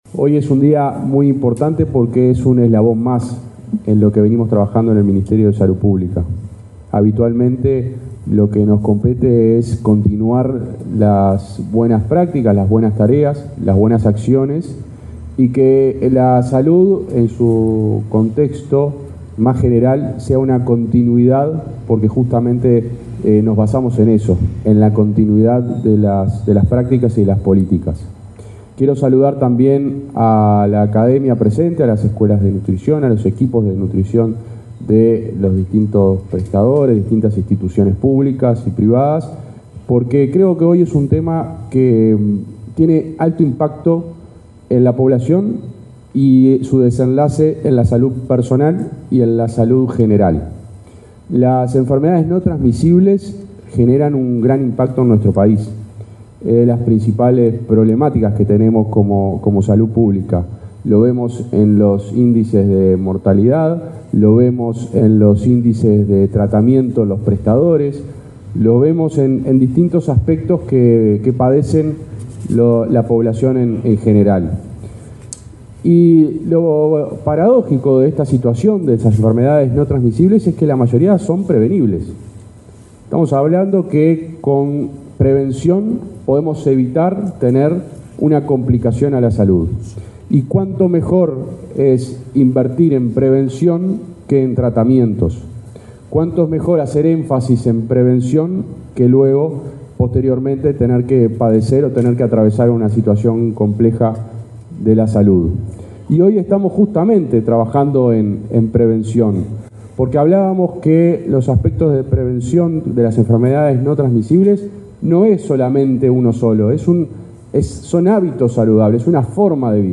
Palabras de autoridades en acto del MSP